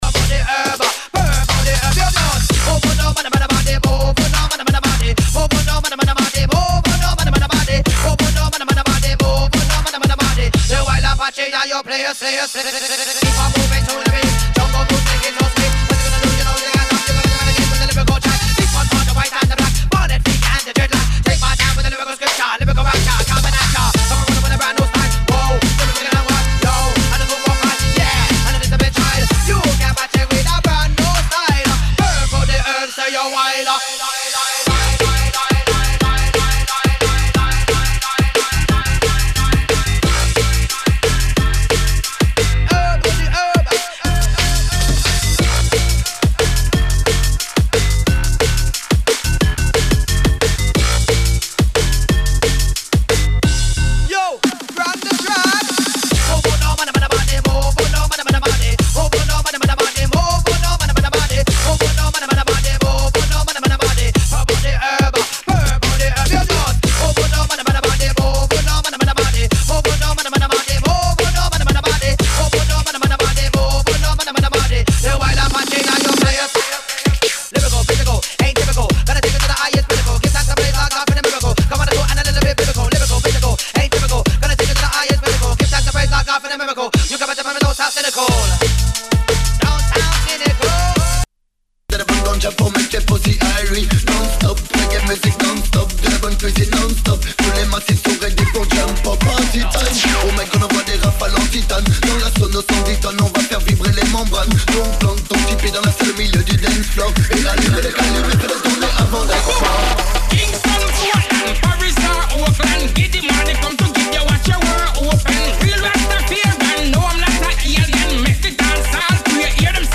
⌂ > Vinyly > Jungle-Drum&Bass >